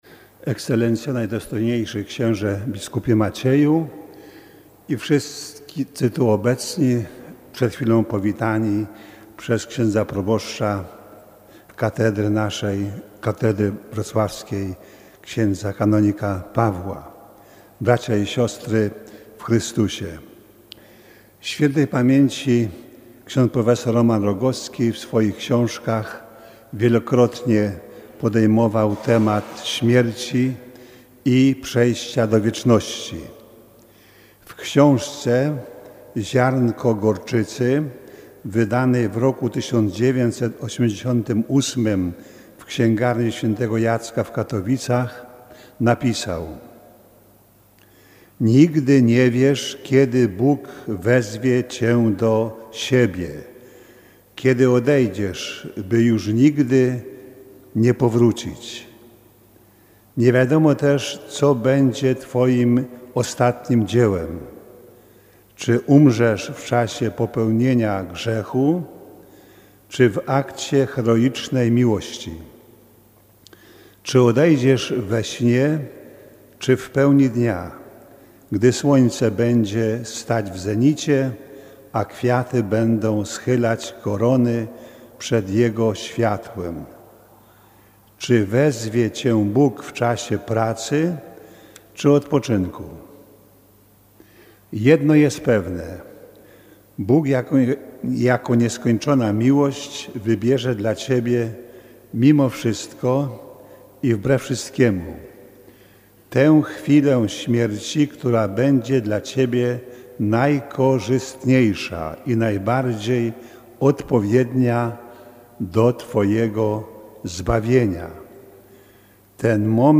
Homilię podczas Mszy pogrzebowej wygłosił były rektor Papieskiego Wydziału Teologicznego we Wrocławiu i biskup senior diecezji świdnickiej, ks. prof. Bp Ignacy Dec.